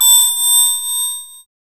5207R SYNBEL.wav